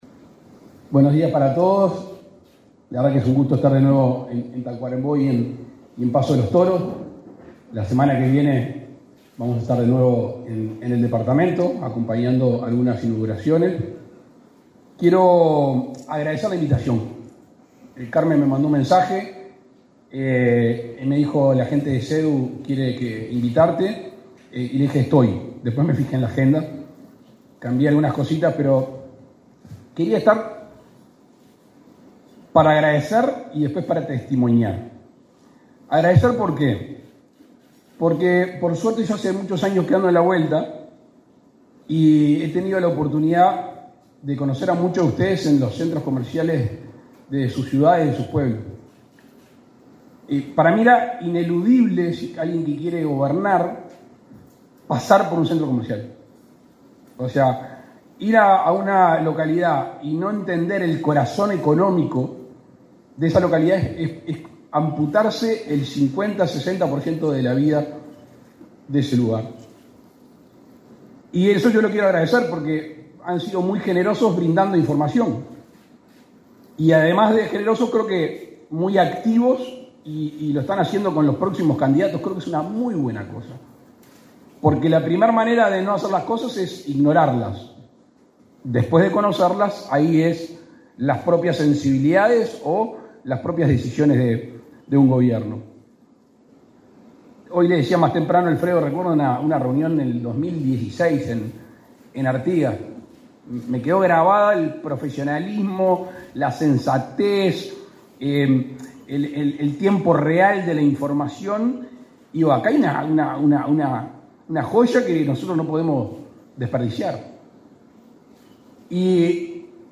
Palabras del presidente de la República, Luis Lacalle Pou
El presidente de la República, Luis Lacalle Pou, participó, este 21 de junio, en el encuentro de presidentes y directivos de asociaciones y centros